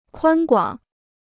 宽广 (kuān guǎng): wide; broad.